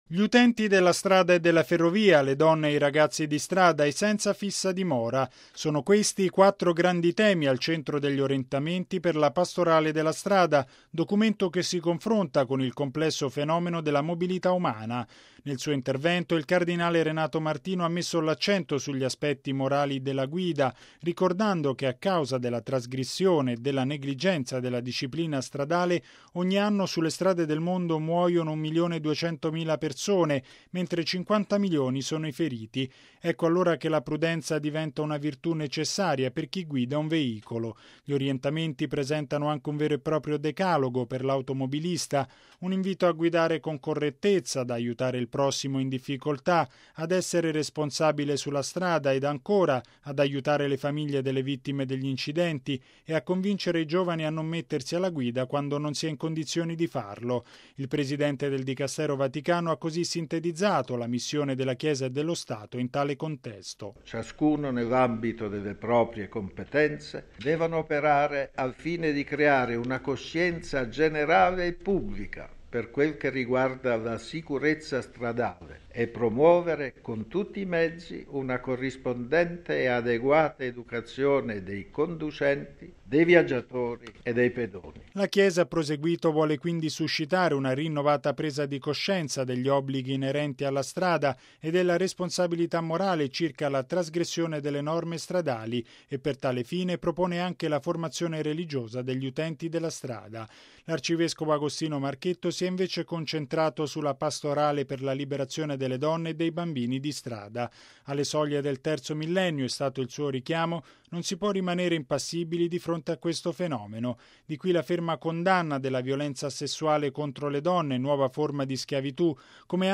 ◊   Presentato stamani, nella Sala Stampa della Santa Sede, il documento "Orientamenti per la Pastorale della Strada".
Il documento è stato presentato dal cardinale Renato Raffaele Martino e dall’arcivescovo Agostino Marchetto, rispettivamente presidente e segretario del Pontificio Consiglio della Pastorale per i migranti e gli itineranti.